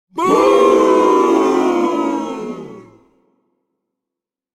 Funny Booing Sound Effect
Description: Funny booing sound effect. A group of people expresses dissatisfaction with loud, funny, cartoonish, or playful booing and crowd reactions.
Funny-booing-sound-effect.mp3